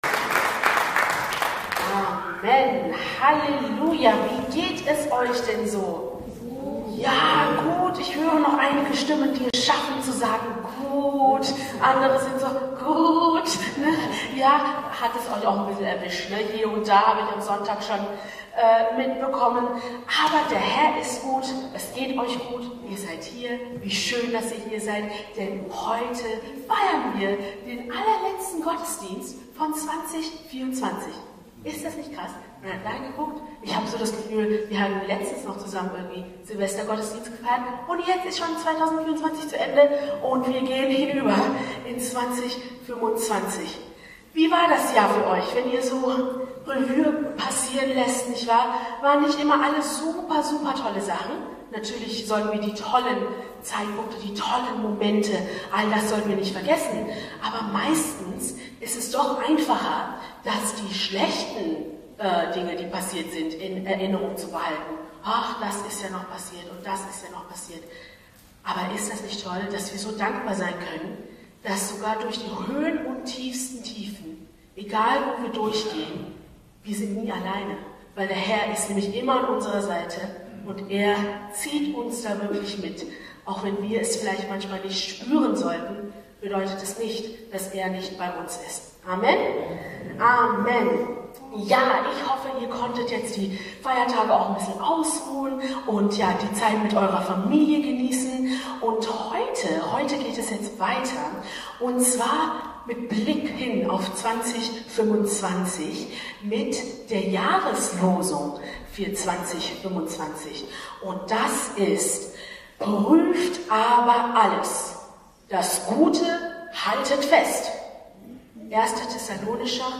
Silvester Gottesdienst – „Prüfet alles!